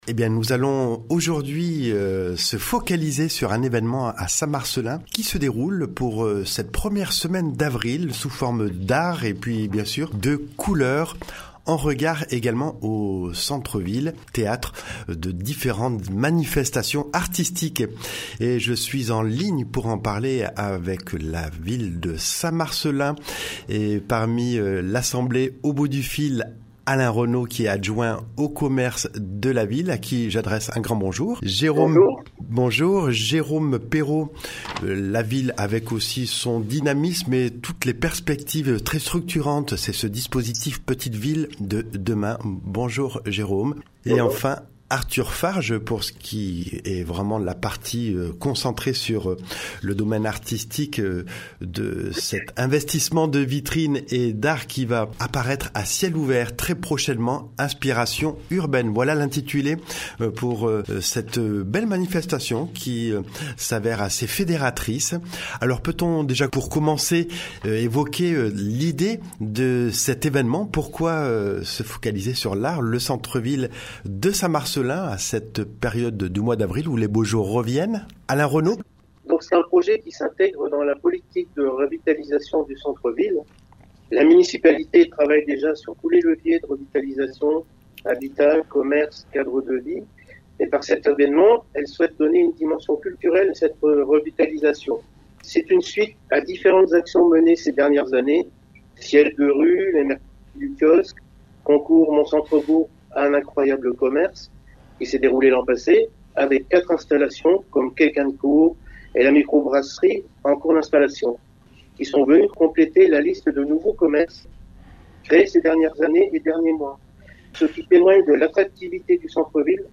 Pour nous présenter cet événement nous nous sommes entretenus par liaison tel.